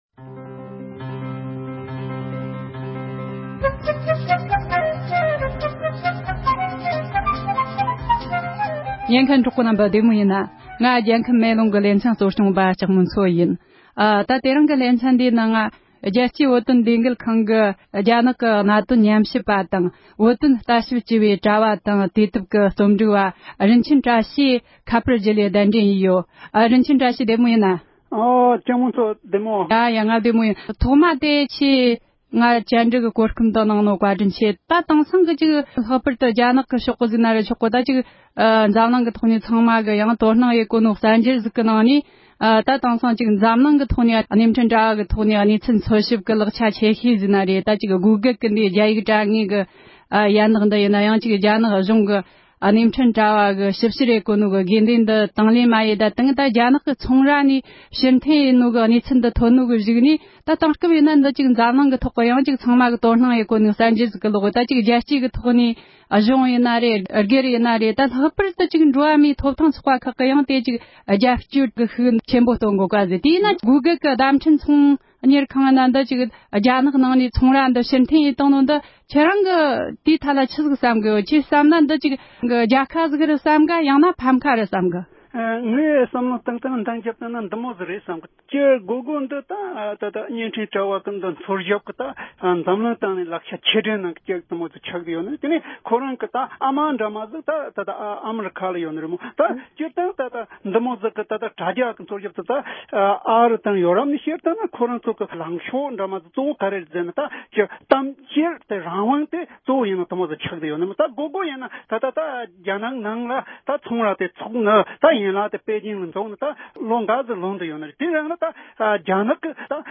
གུ་གུལ་ཐད་གླེང་མོལ།